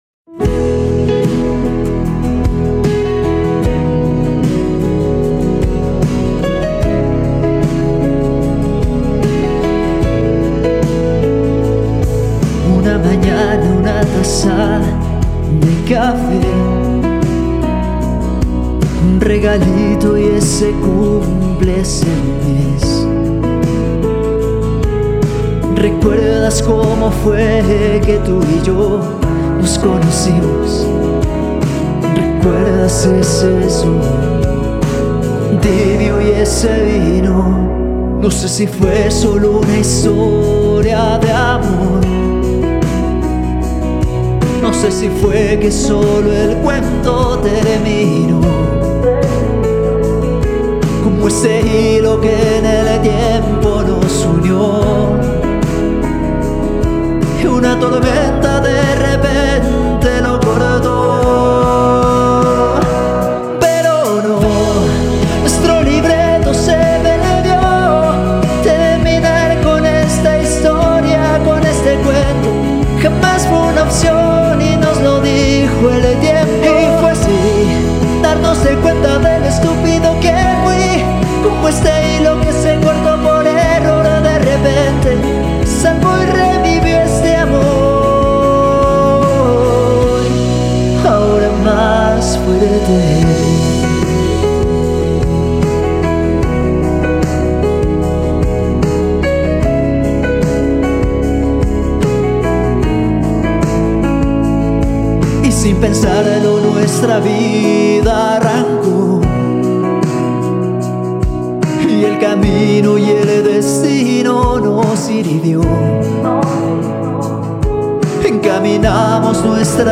artista pop latino